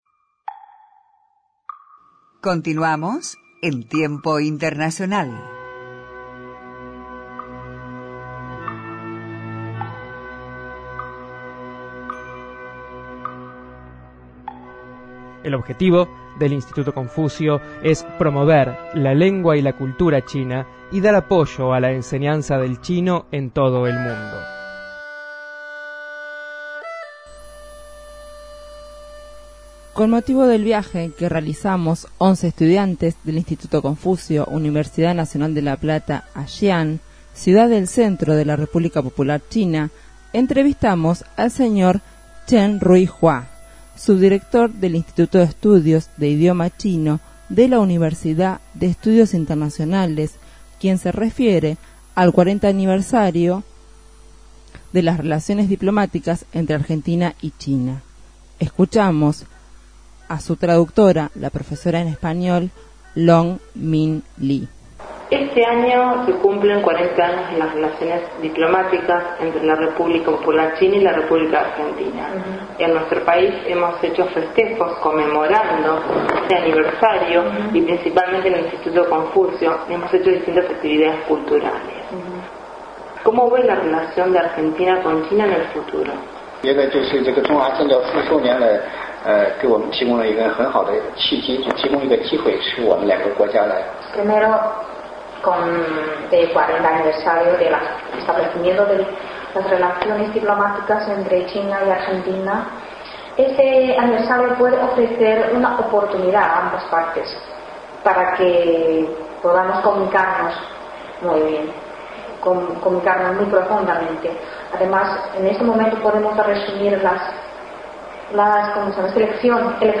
Entrevistado: Embajador de la República de Japón en Argentina Masashi Mizukami quien ejerce la función desde febrero de 2012, se refiere a la cultura y hábitos japoneses.